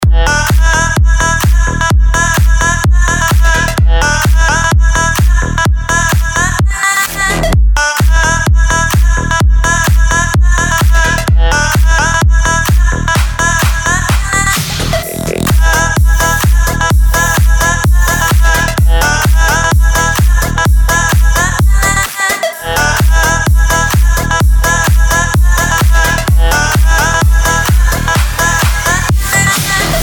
Чумовой Dutch House с крутыми Лидами!)